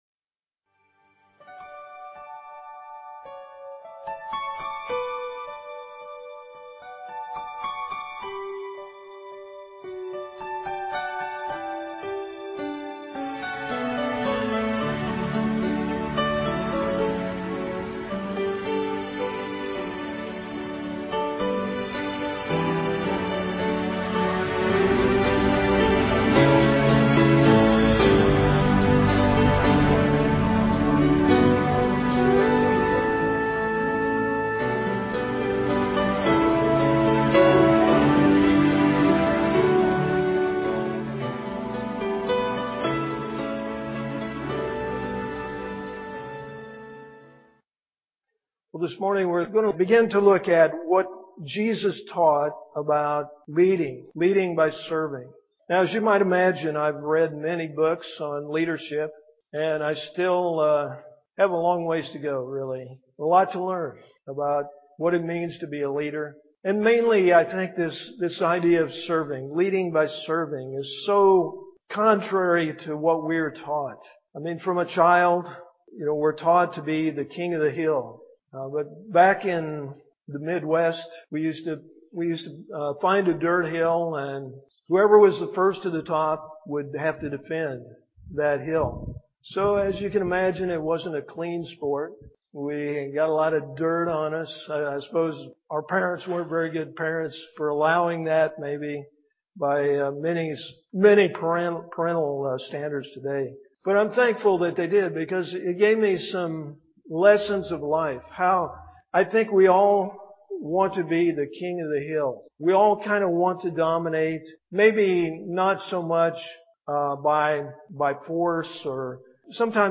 at Ewa Beach Baptist Church. Musical Intro/Outro: "How Beautiful."